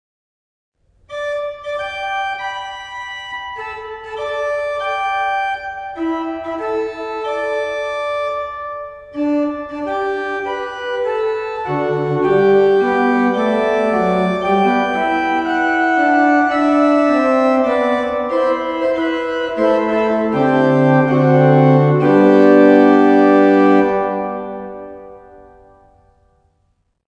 Orgelvorspiele
Hier finden Sie einige Orgelvorspiele zu Liedern aus dem Gotteslob.
gg_932_uns_zum_himmel_zu_erheben_orgelvorspiel.mp3